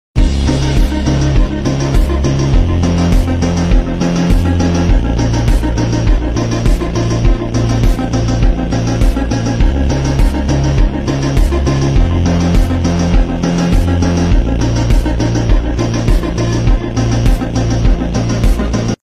snow sound effects free download